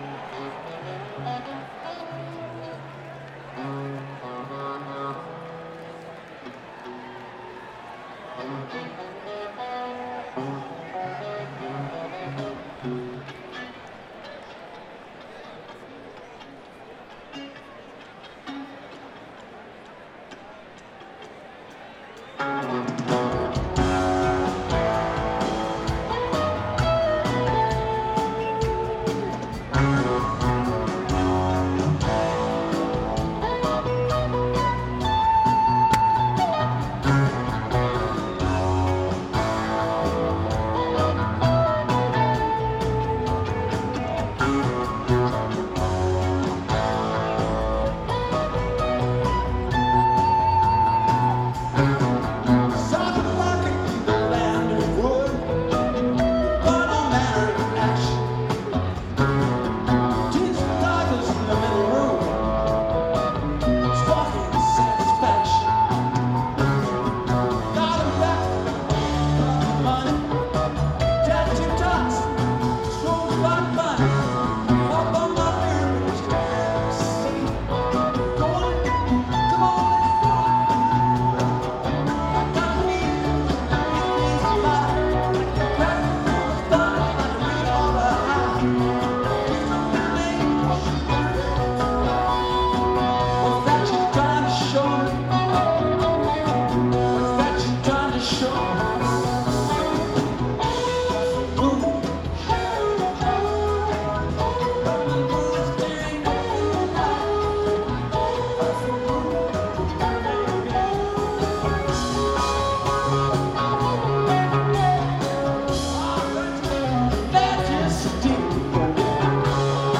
The band went for an 80s pychedelia feel for the show